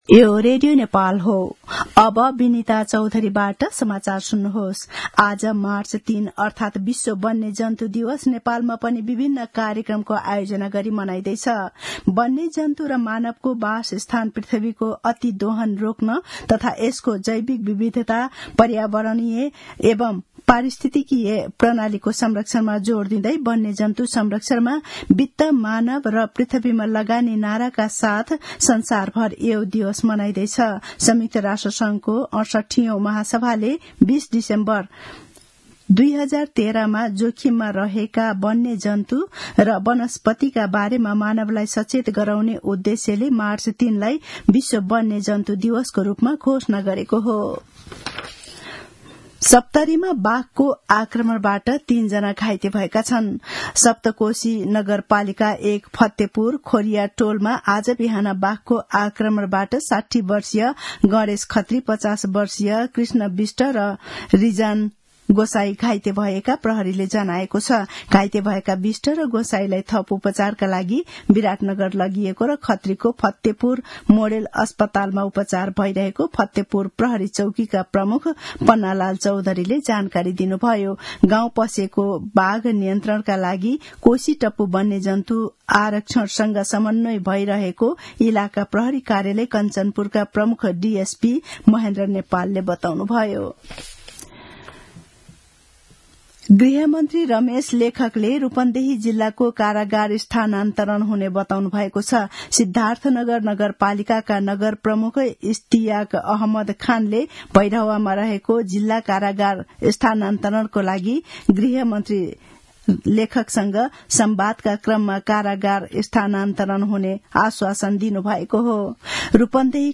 An online outlet of Nepal's national radio broadcaster
मध्यान्ह १२ बजेको नेपाली समाचार : २० फागुन , २०८१
12-am-news.mp3